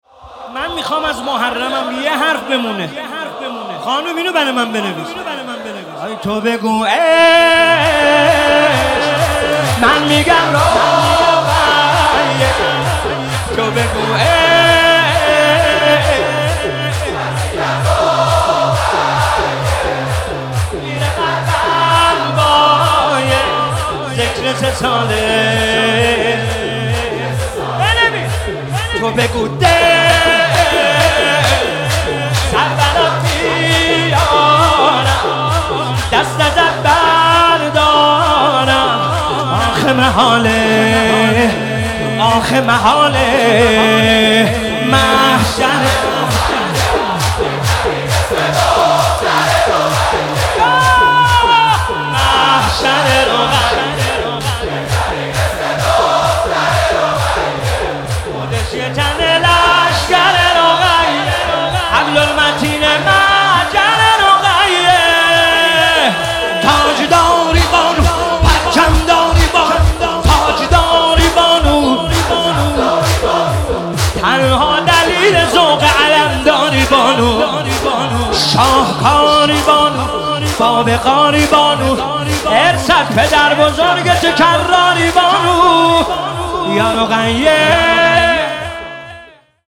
مداحی ها